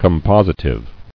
[com·pos·i·tive]